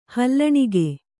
♪ hallaṇige